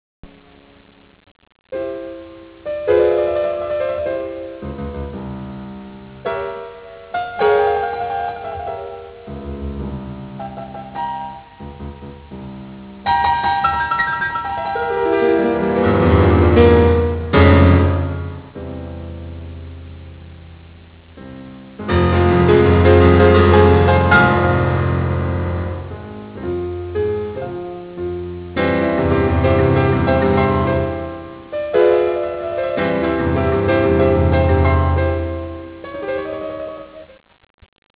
特に豊かに鳴る低音域の和音で力強さを出し、 同時に高音域のパッセージを奏でることなどにより、一層のダイナミックな表現を演出している。